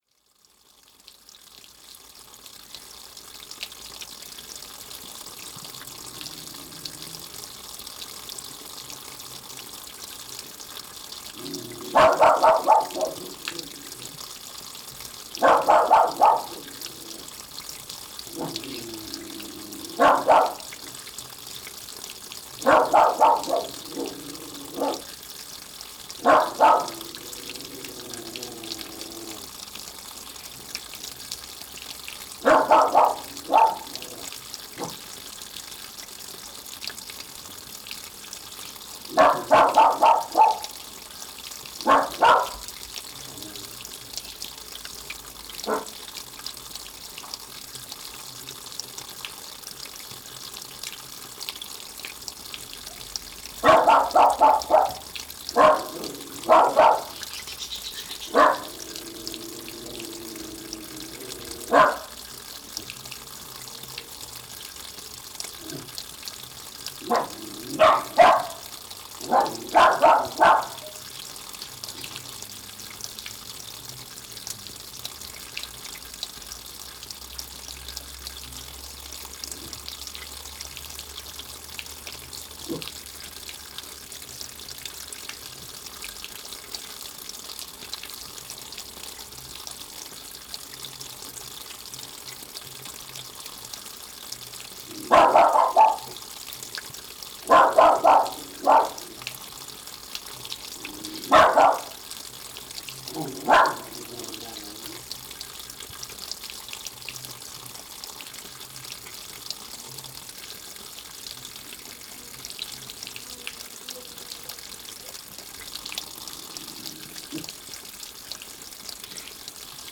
Paisagem sonora de escoamento de água de fonte e um cão a ladrar em Maeira de Baixo, Barreiros e Cepões a 14 Março 2016.
NODAR.00565 – Barreiros e Cepões: Escoamento de água de fonte e um cão a ladrar em Maeira de Baixo